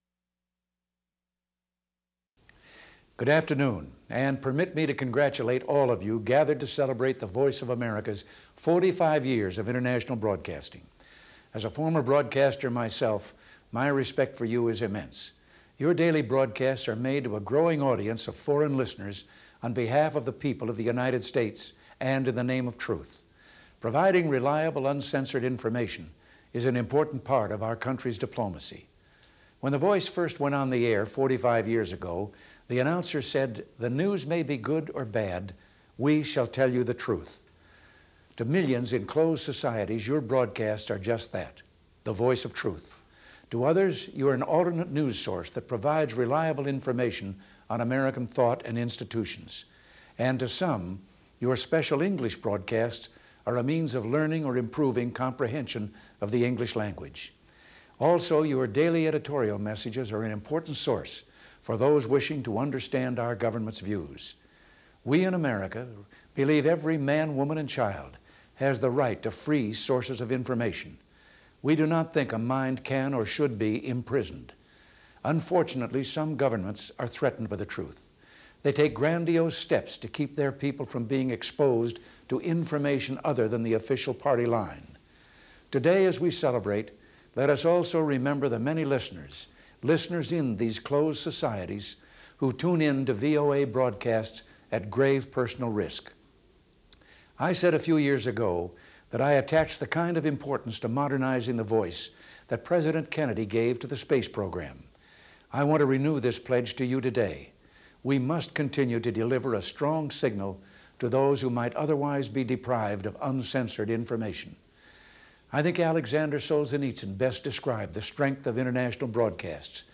President Ronald Reagan thanks the Voice of America in this broadcast on its 45th anniversary. Reagan praises the efforts of the VOA to broadcast truth to those in the world who are still oppressed.
Broadcast on Voice of America, Feb. 24, 1987.